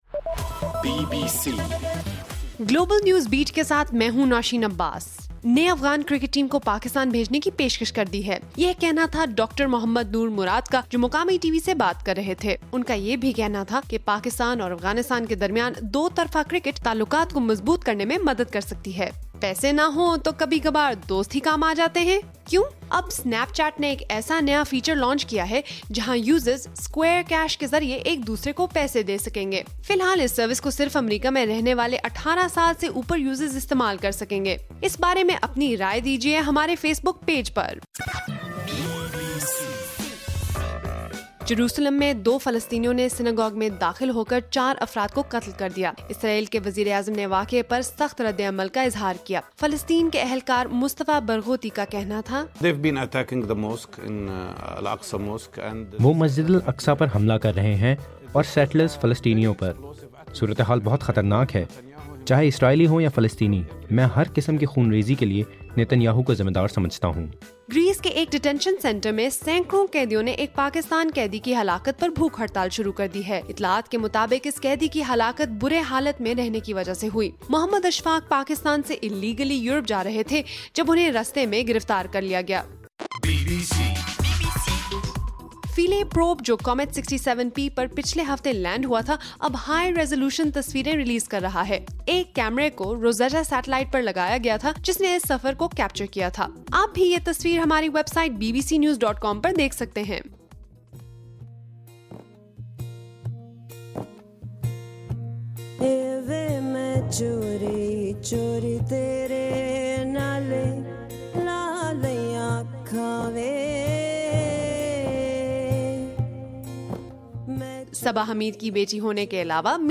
نومبر 19: صبح 1 بجے کا گلوبل نیوز بیٹ بُلیٹن